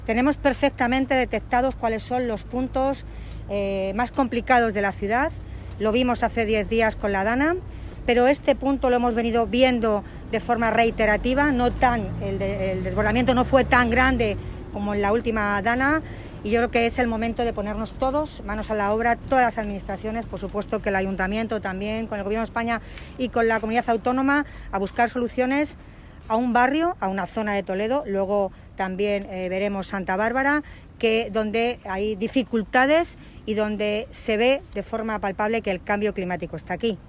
En declaraciones a los medios en el barrio de Azucaica, Milagros Tolón ha agradecido la implicación, apoyo y compromiso del Gobierno de España para buscar entre todas las administraciones posibles soluciones para paliar los desperfectos y realizar las obras que son precisas acometer y así evitar desbordamientos como los acaecidos en los últimos días que podrían convertirse en habituales debido a las consecuencias del cambio climático.